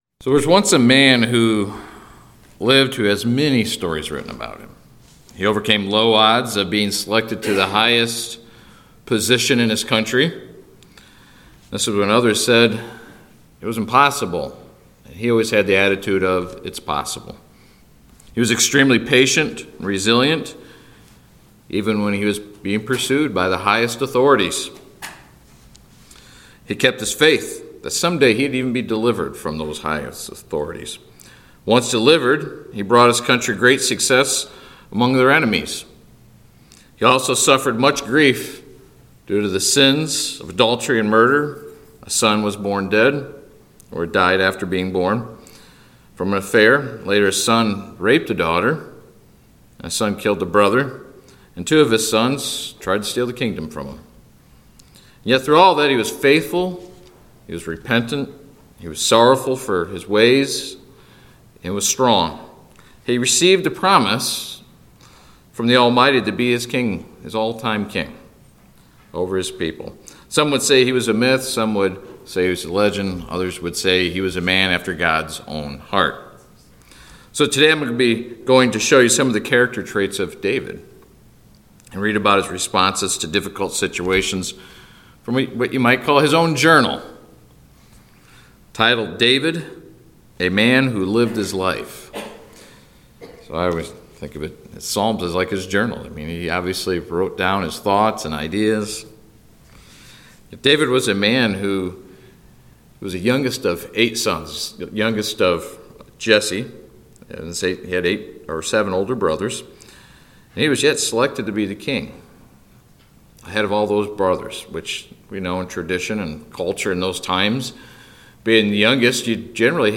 In this sermon we will go over some of David's character traits as well as some of the difficult situations that he experienced and his responses to them.
Given in Grand Rapids, MI